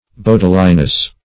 Bodiliness \Bod"i*li*ness\, n.